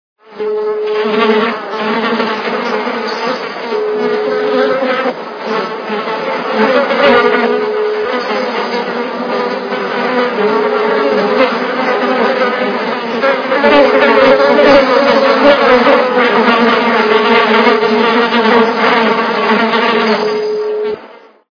Жужжание - Дзижчання-Пчелы или зеленые МУХИ Звук Звуки Дзижчання - Пчелы или зеленые МУХИ
» Звуки » Природа животные » Жужжание - Дзижчання-Пчелы или зеленые МУХИ
При прослушивании Жужжание - Дзижчання-Пчелы или зеленые МУХИ качество понижено и присутствуют гудки.